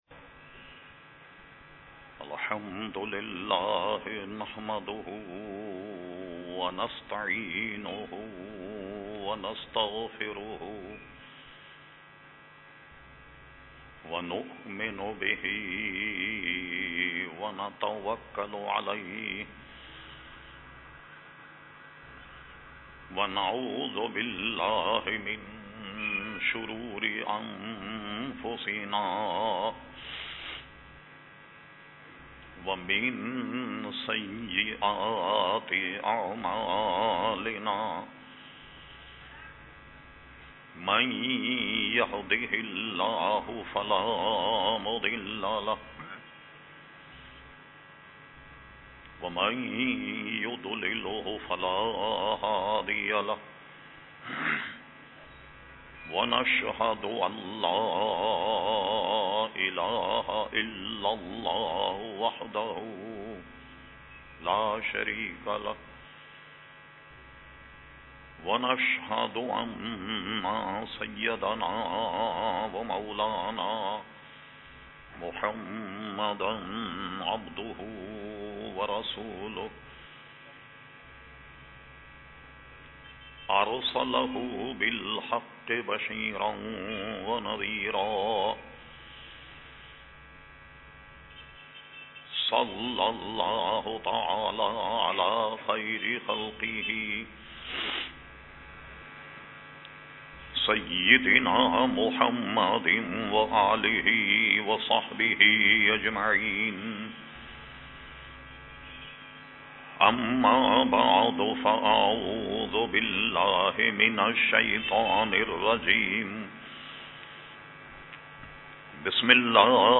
Category : Speeches | Language : Urdu